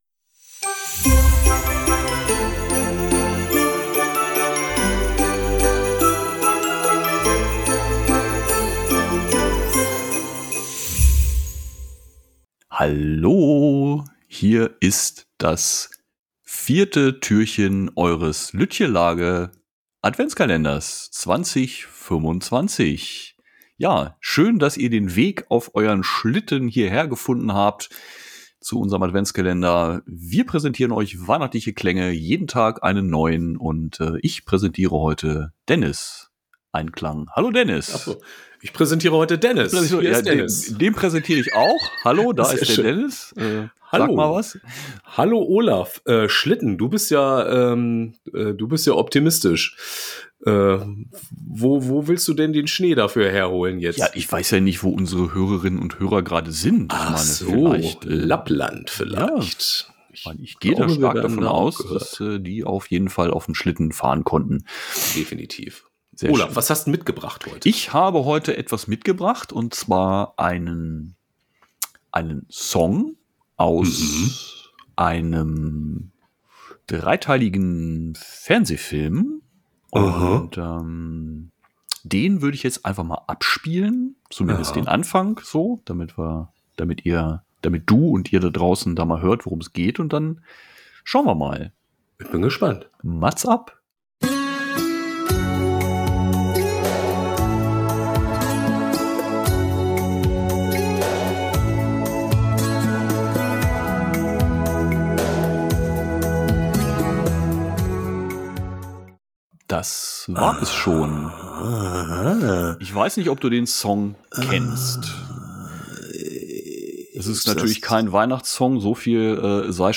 nächsten Weihnachtssound. Ratezeit für Türchen 4!